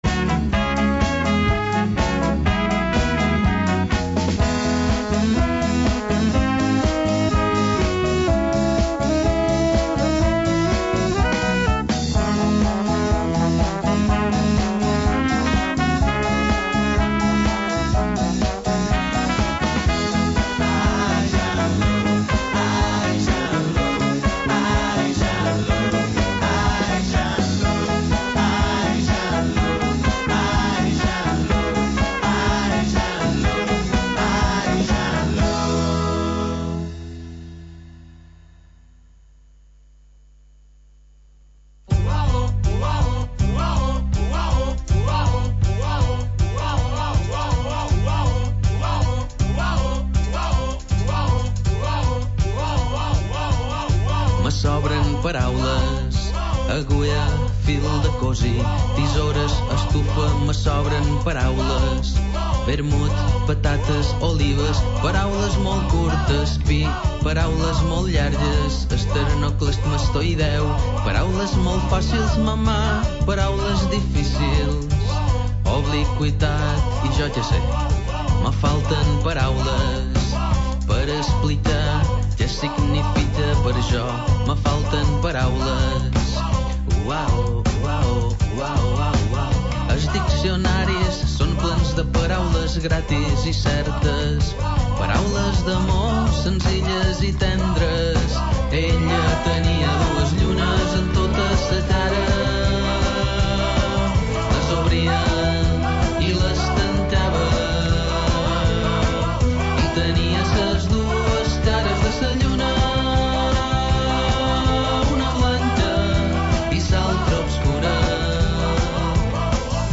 L'alcalde de l'Escala a disposició dels oients